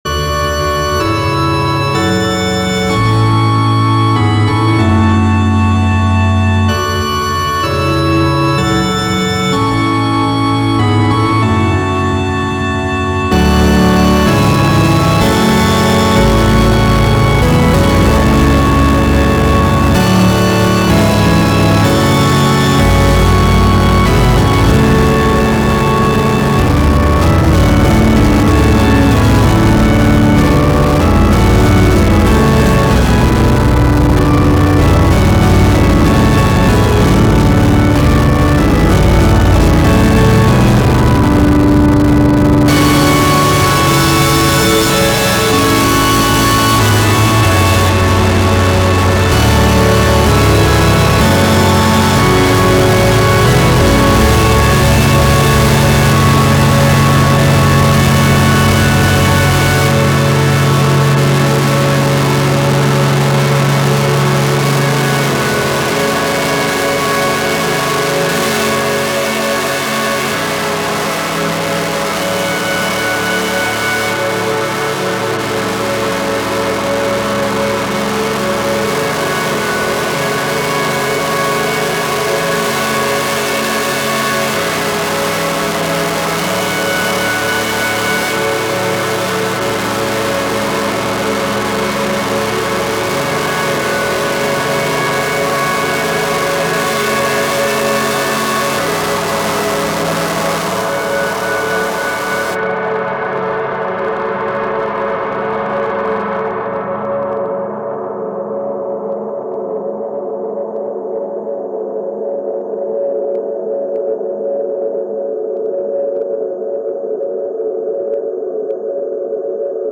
Genre: Noise Rock/Metal